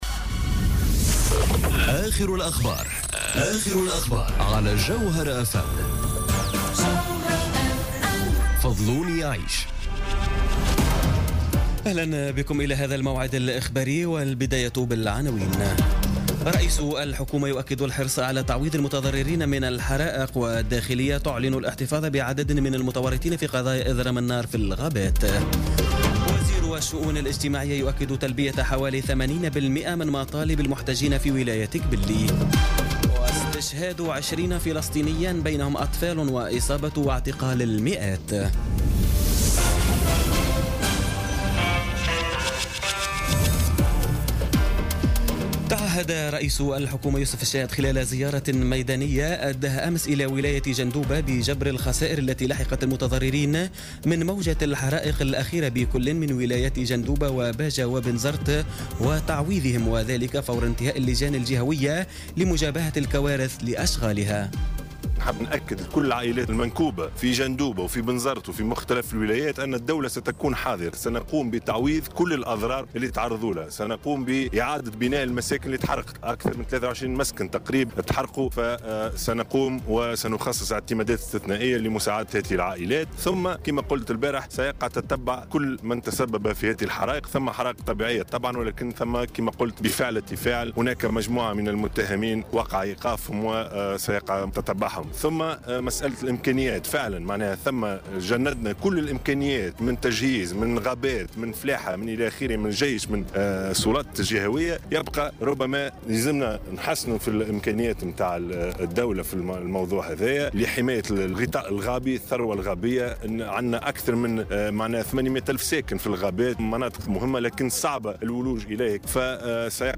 نشرة أخبار منتصف الليل ليوم السبت 5 أوت 2017